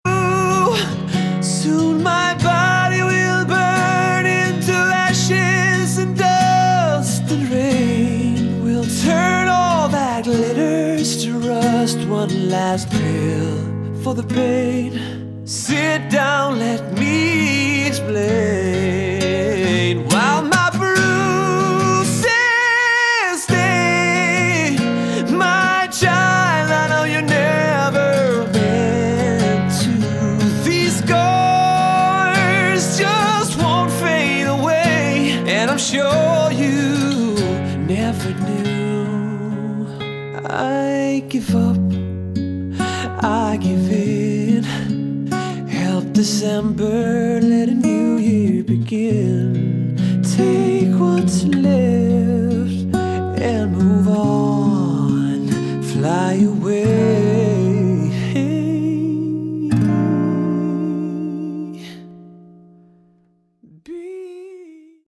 Category: Prog Rock
bass
guitar
vocals
keyboard
drums